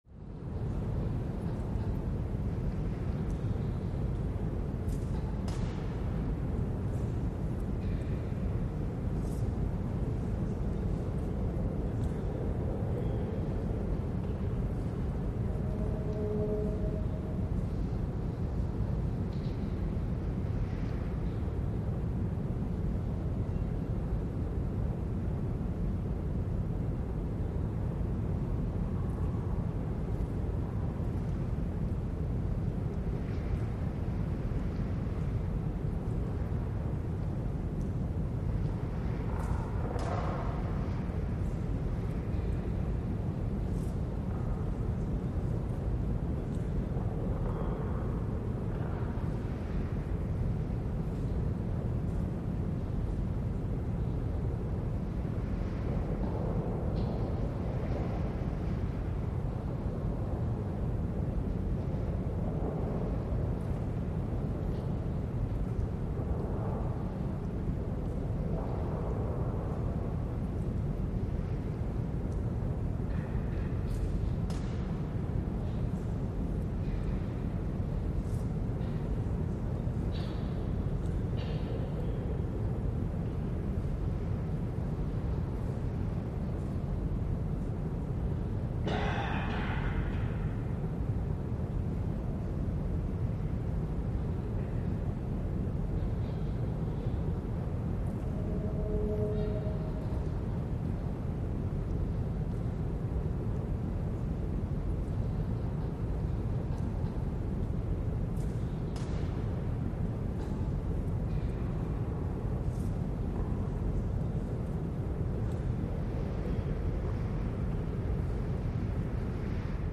Large, Reverberant, Airy Hall With Light Movement And City Hum.